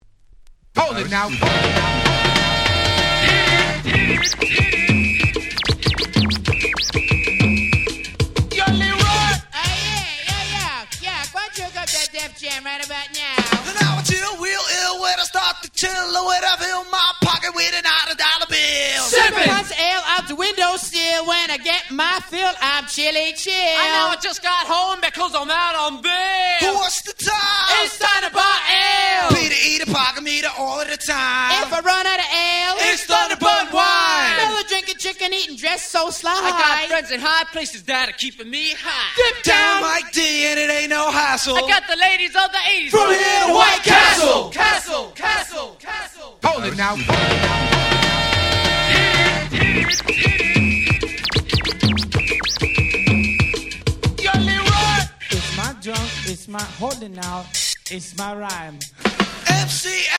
87' Super Hit Hip Hop !!
Old School